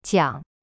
If you listen closely and compare the two, the jiang3 one has the tone go up towards the end, whereas jiang4 just drops off with no upward tonal shift towards the end.